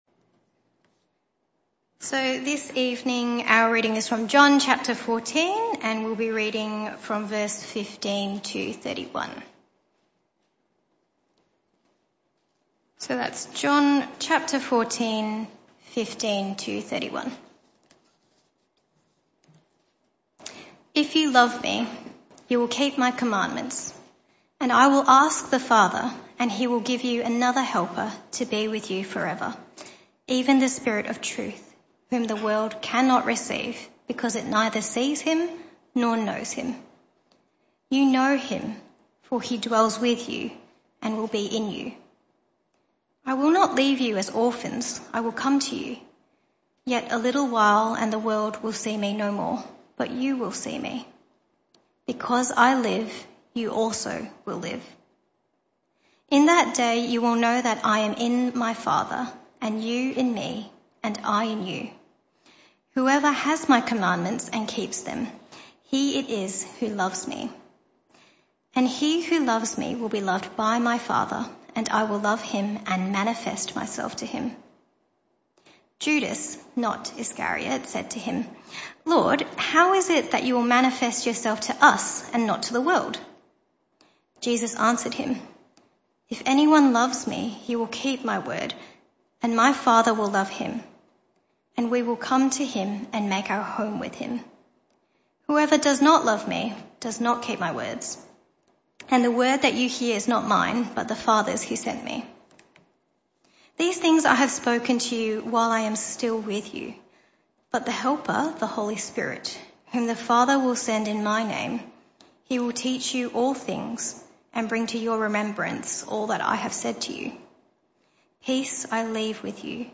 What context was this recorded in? This talk was a one-off talk in the PM Service.